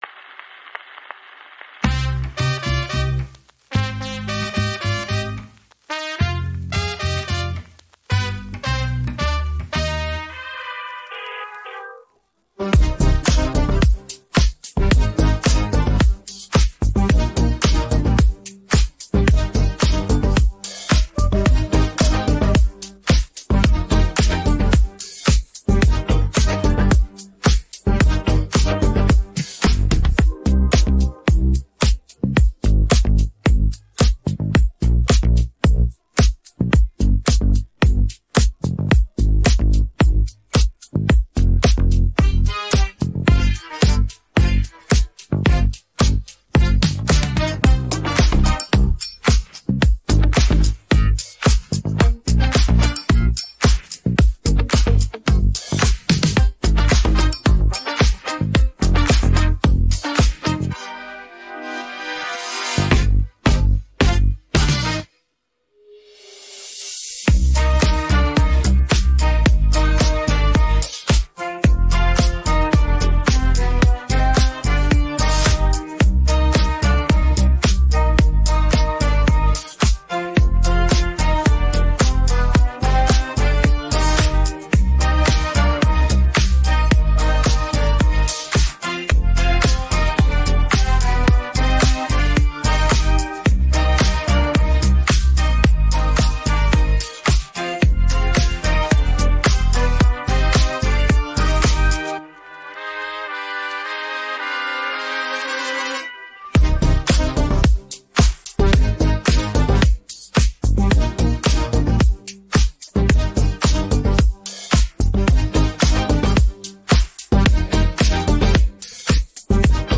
енергійний гімн, ідеальний для караоке онлайн!